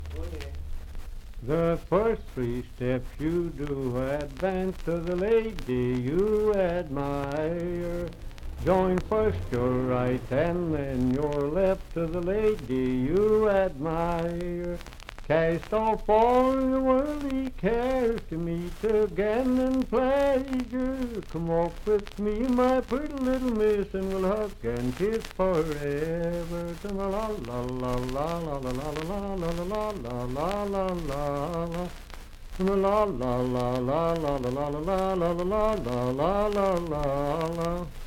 Unaccompanied vocal music
Dance, Game, and Party Songs
Voice (sung)
Franklin (Pendleton County, W. Va.), Pendleton County (W. Va.)